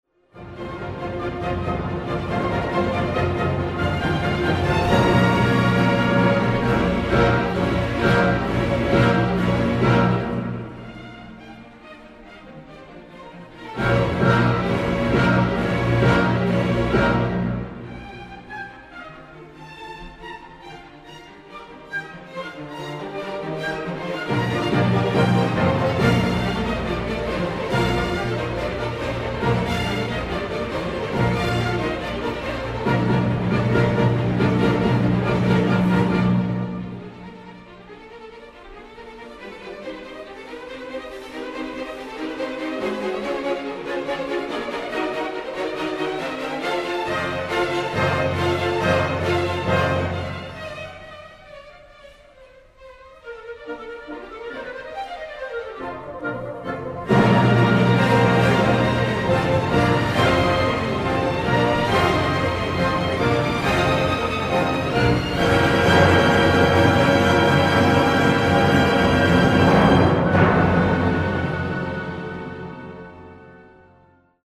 Sinfonía no. 4Ludwig van Beethoven